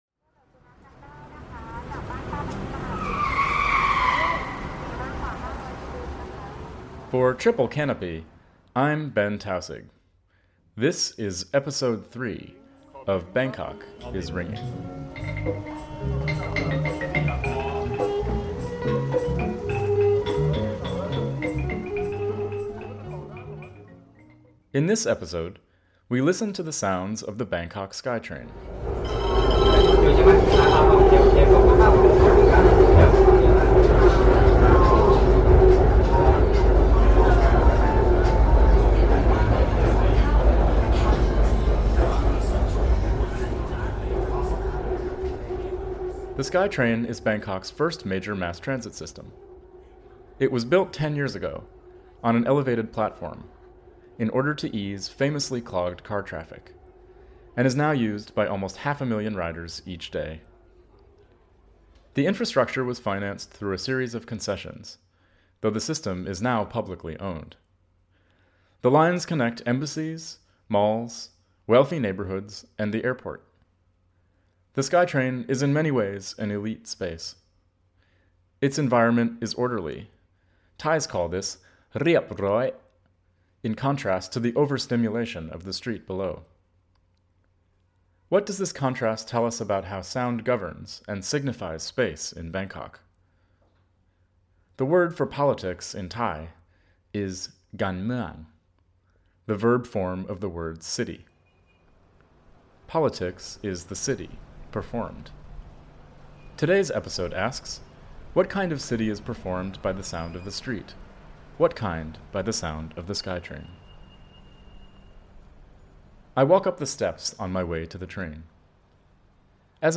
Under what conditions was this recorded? A series exploring the politics of urban sound in Bangkok and beyond, through first-person reporting, field recordings, and analysis.